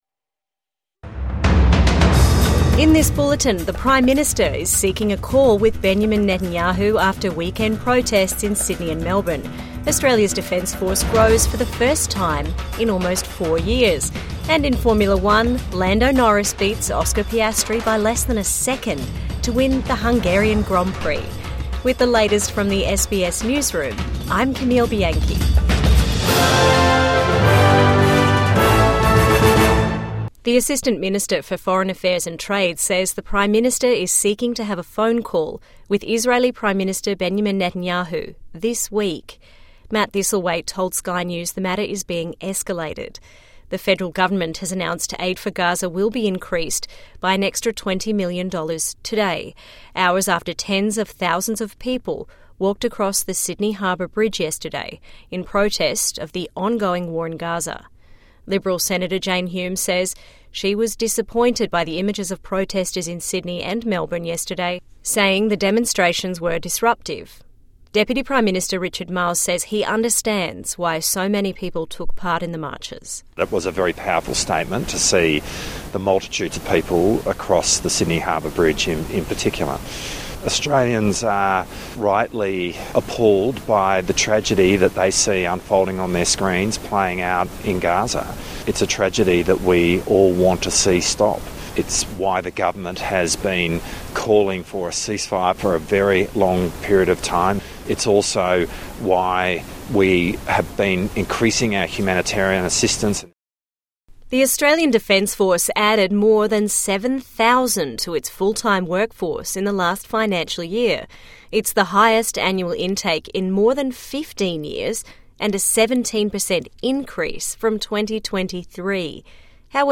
News Bulletin